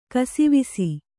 ♪ kasivisi